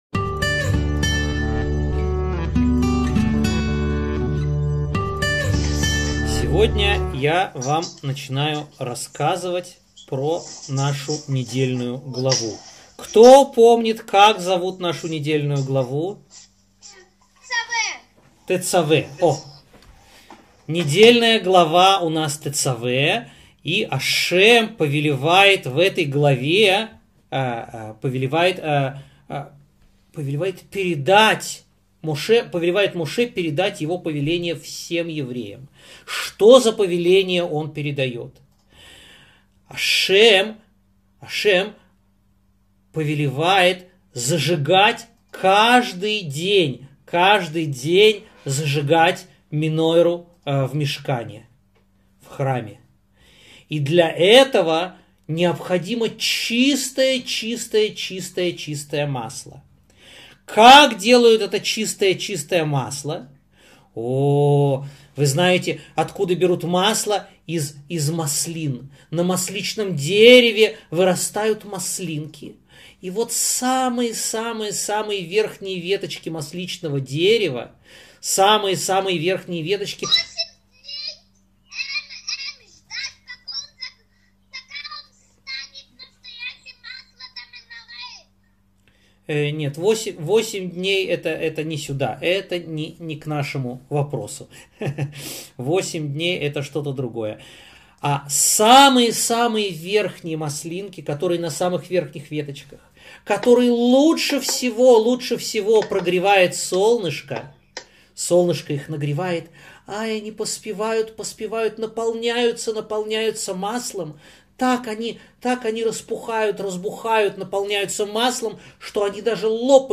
рассказывает детям о событиях, описанных в Торе